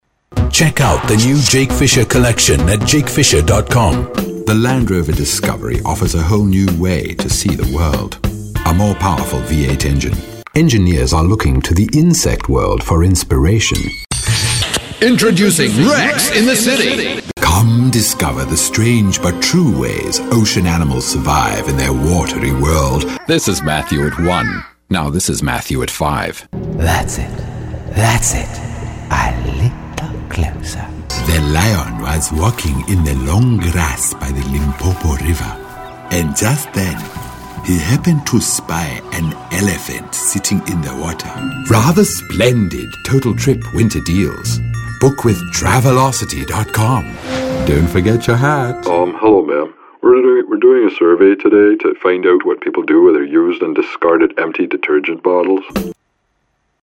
Rich, Distinctive, International, South African, Pan-African, Voice-over , Accents, Mid-Atlantic
Sprechprobe: Werbung (Muttersprache):